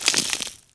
autocannon_grenade_pickup3.wav